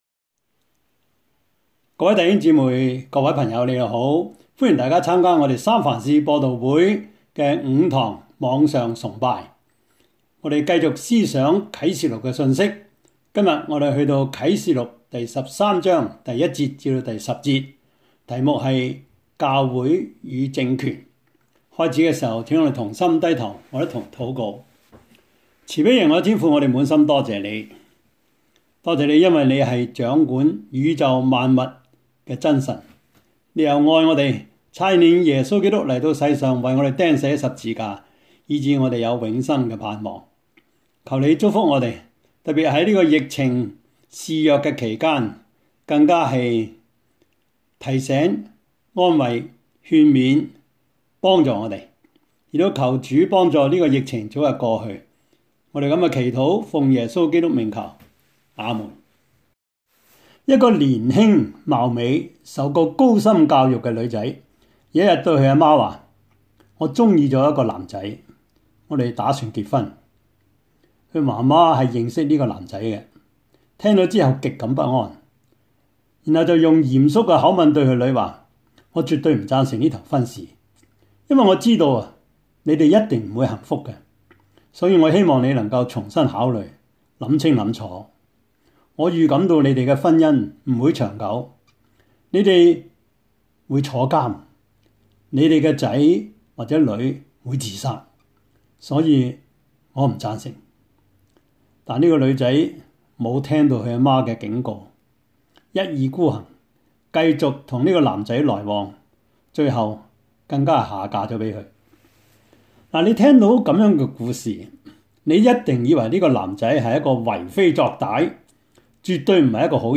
Service Type: 主日崇拜
Topics: 主日證道 « 從 “心” 開始 第三十一課:英國的宗教改革 2 »